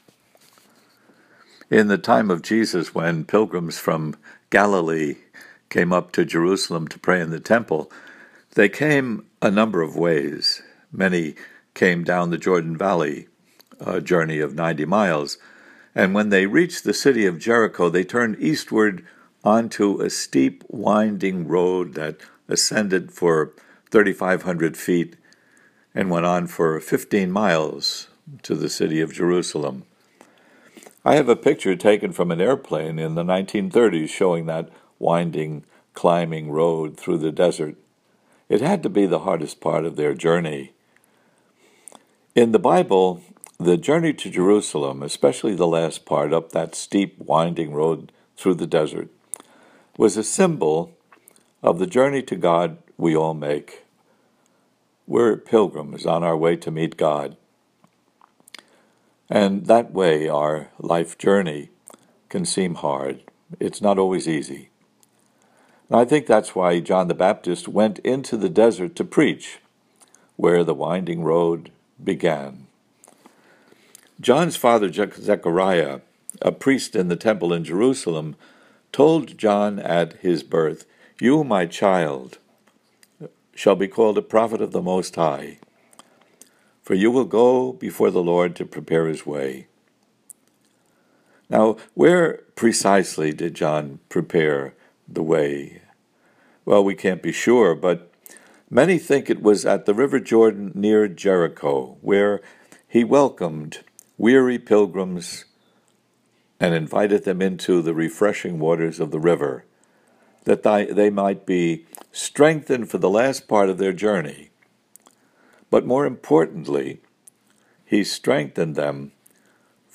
Audio homily here: